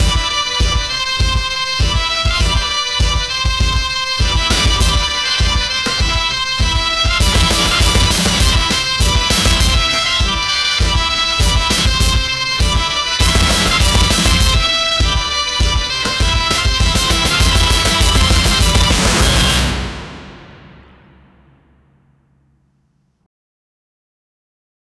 old-man-poop-himself-and-baut5la5.wav